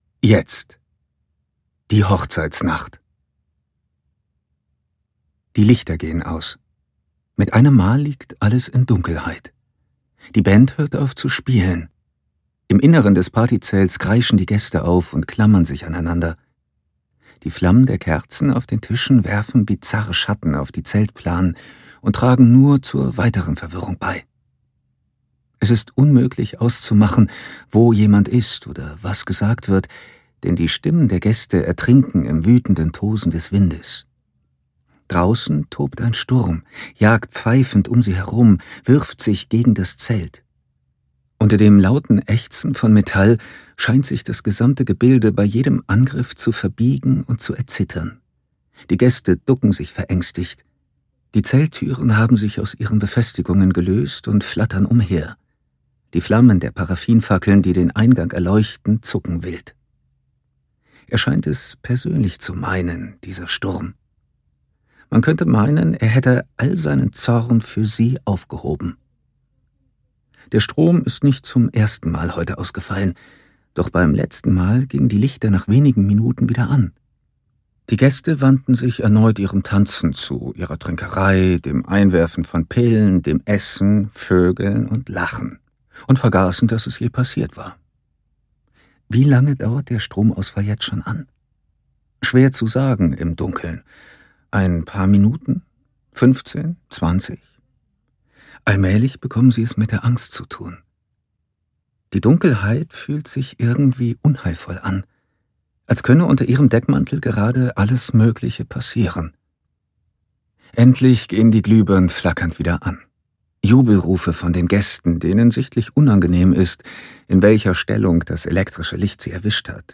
Sommernacht (DE) audiokniha
Ukázka z knihy